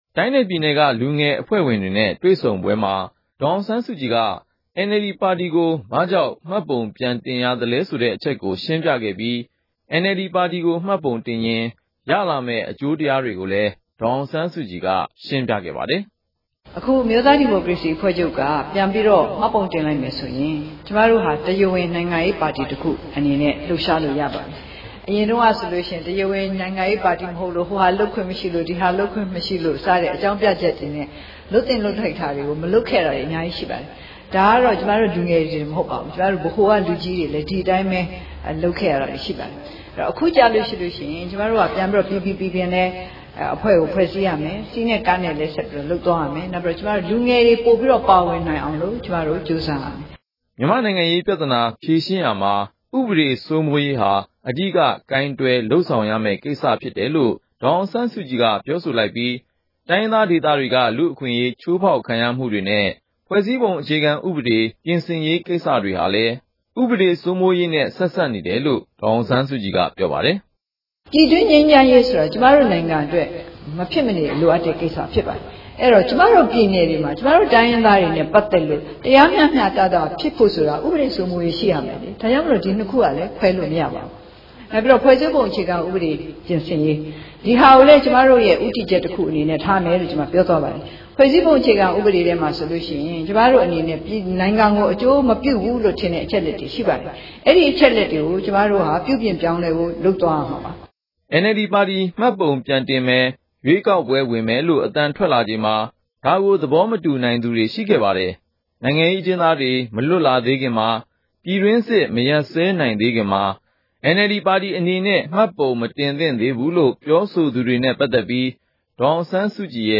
assk-nld-decision-305 အမျိုးသား ဒီမိုကရေစီအဖွဲ့ချုပ် NLD ပါတီ မှတ်ပုံပြန်တင်မည့် ဆုံးဖြတ်ချက်နှင့် ပတ်သက်ပြီး ၂ဝ၁၁ ခုနှစ် နိုဝင်ဘာလ ၁၉ ရက်နေ့က ရန်ကုန်မြို့ ရွှေဂုံတိုင်ရုံးချုပ်တွင် NLD လူငယ်ရေးရာ တာဝန်ခံ ဒေါ်အောင်ဆန်းစုကြည် ရှင်းလင်းပြောကြားစဉ်။
စုစည်းတင်ပြချက်။